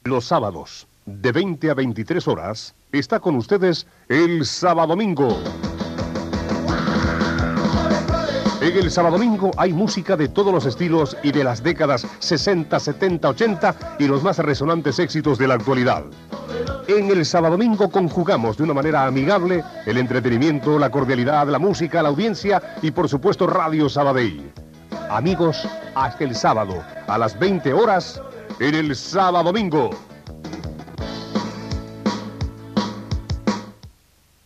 Promoció del programa